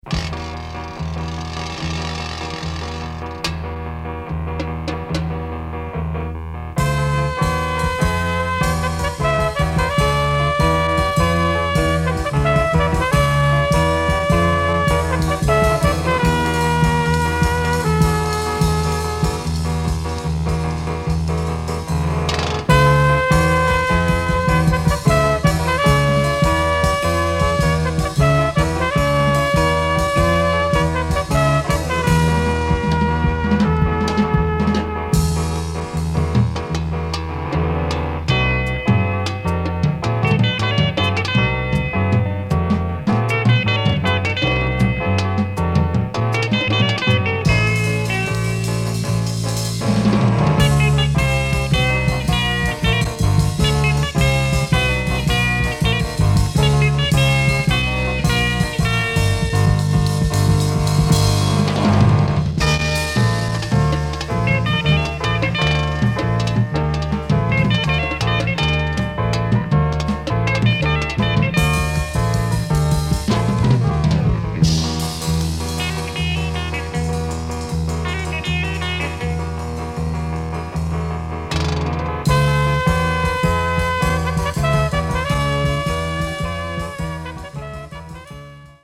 Folk jazz and latin music from Argentina
Vinyl shows marks but plays surprisingly very good.